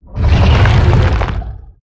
Sfx_creature_shadowleviathan_swimgrowl_os_02.ogg